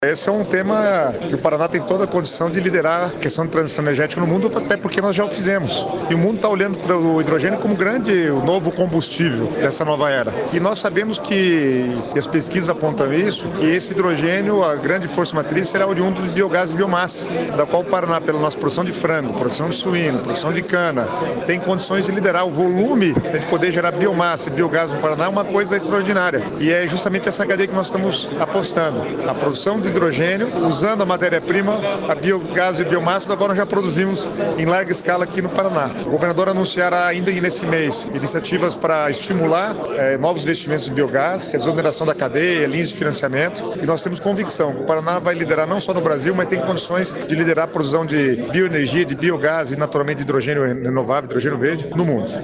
Sonora do secretário do Planejamento, Guto Silva, sobre a capacidade de produção de hidrogênio no Paraná
GUTO SILVA - EVENTO GAZETA HIDROGENIO.mp3